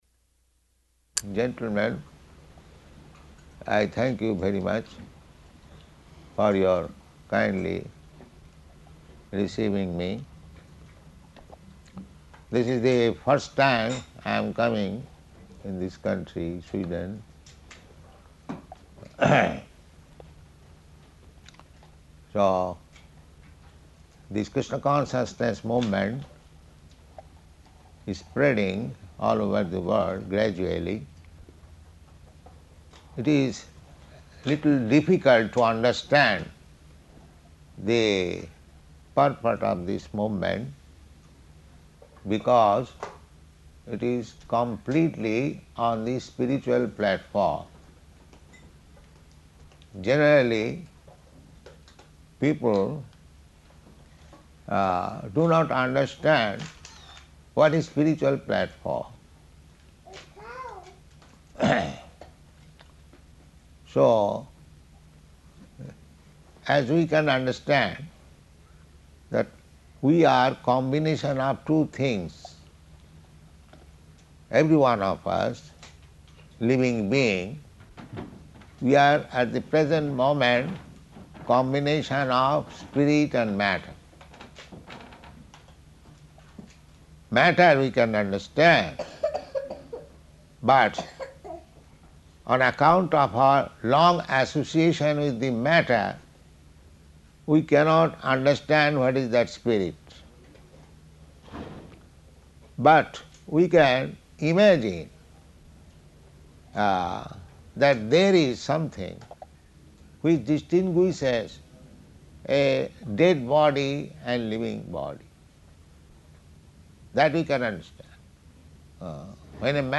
Arrival Speech
Arrival Speech --:-- --:-- Type: Lectures and Addresses Dated: September 5th 1973 Location: Stockholm Audio file: 730905AR.STO.mp3 Prabhupāda: ...and gentlemen, I thank you very much for your kindly receiving me.